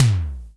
Index of /90_sSampleCDs/Roland L-CDX-01/DRM_Analog Drums/TOM_Analog Toms
TOM SIMM T4.wav